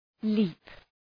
Shkrimi fonetik {li:p}